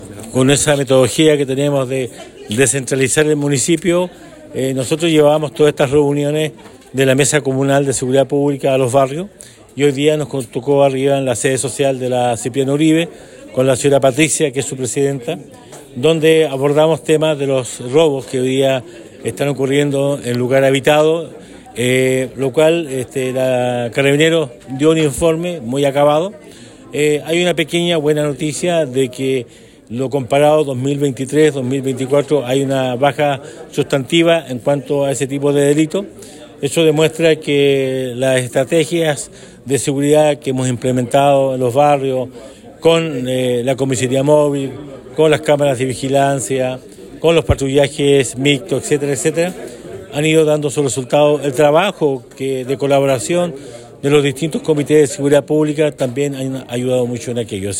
El Alcalde Emeterio Carrillo, señaló que descentralizar estas reuniones permite avanzar con la seguridad y el bienestar de los vecinos de Osorno fomentando el trabajo conjunto entre autoridades, Carabineros, Fiscalía y la comunidad en general.